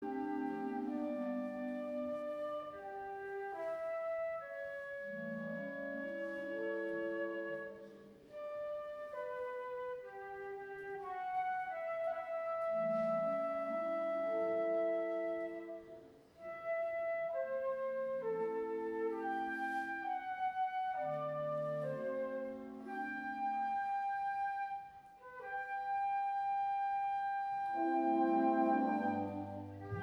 Concert du 18 Décembre 2016, Centre Culturel Louis Jouvet à Bonnières-Sur-Seine